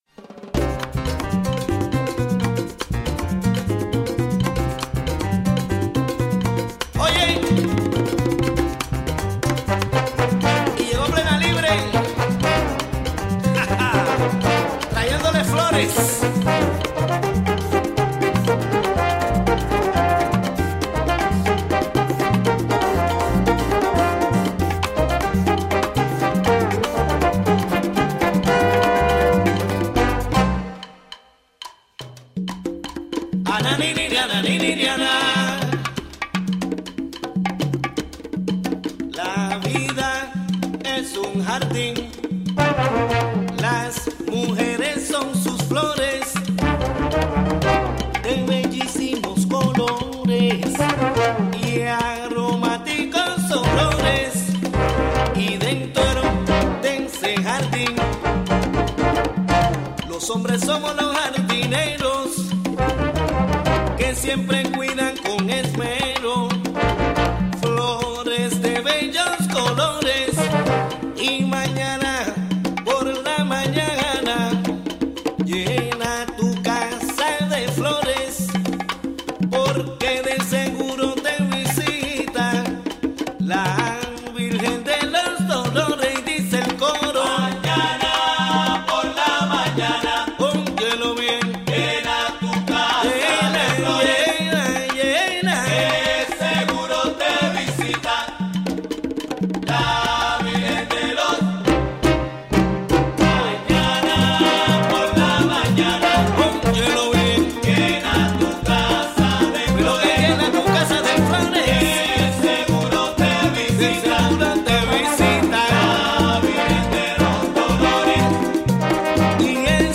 A conversation